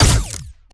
Added more sound effects.
GUNAuto_Plasmid Machinegun B Single_05_SFRMS_SCIWPNS.wav